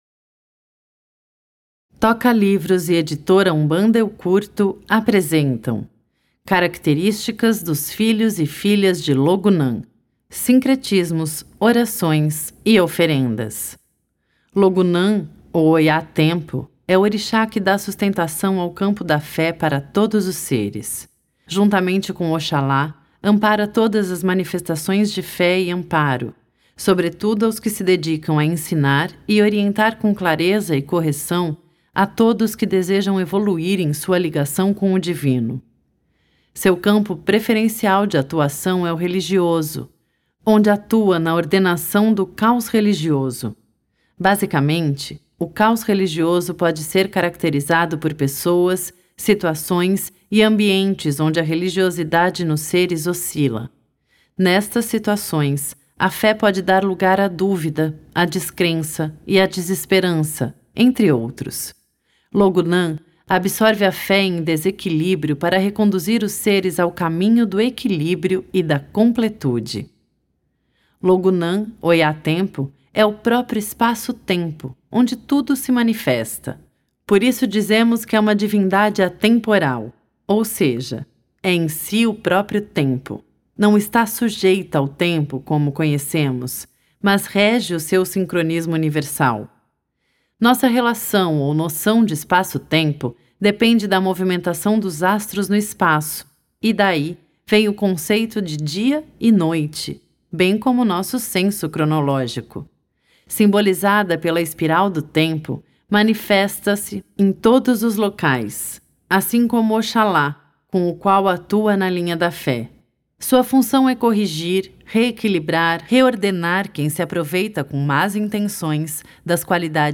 Audiobooks são vendidos através da plataforma Tocalivros.